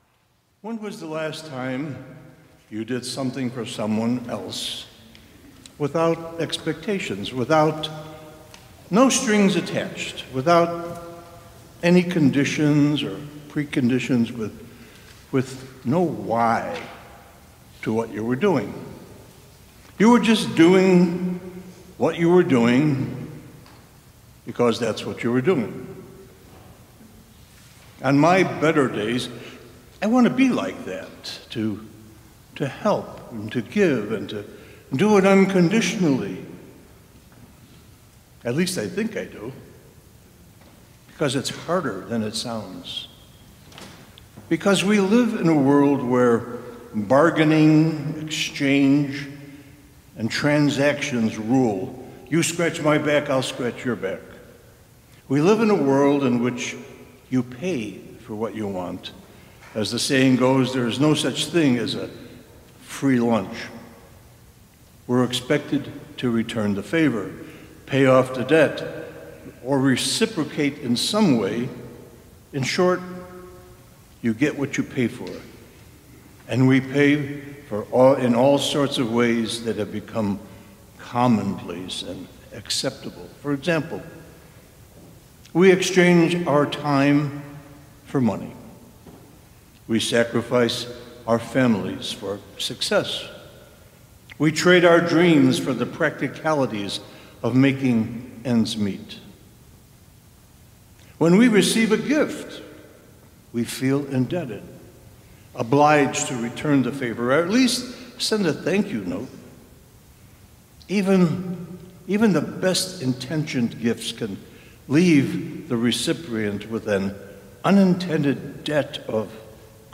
Sermons | Emmanuel Episcopal Church